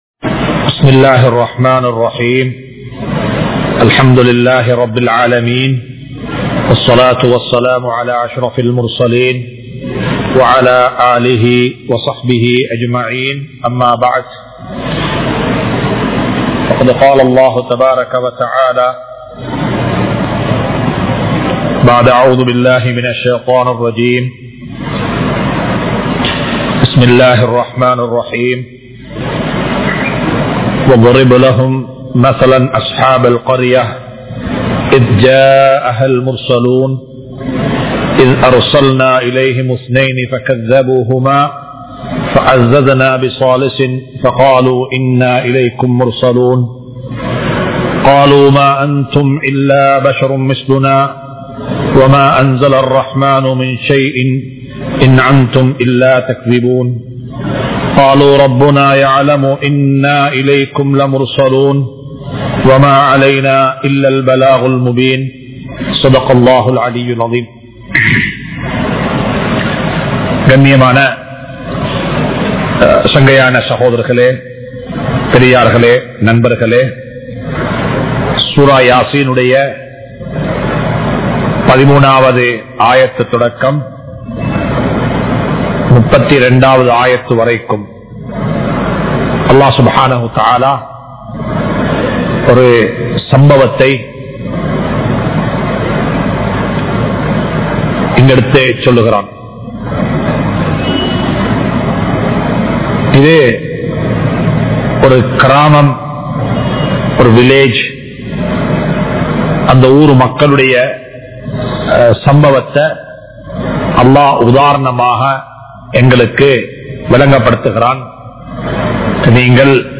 Sura Yaseen 13 To 32 | Audio Bayans | All Ceylon Muslim Youth Community | Addalaichenai
Majma Ul Khairah Jumua Masjith (Nimal Road)